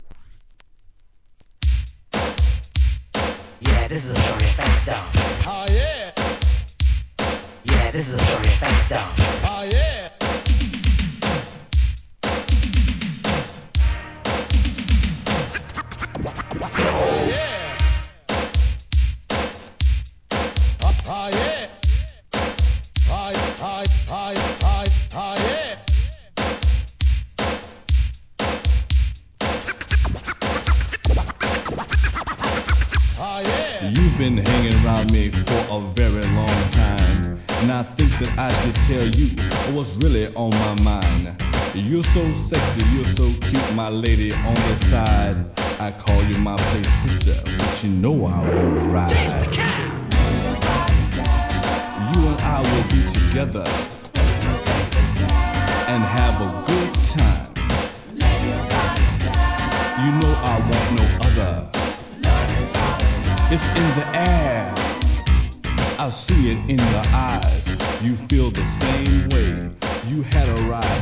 HIP HOP/R&B
エレクトロOLD SCHOOL!!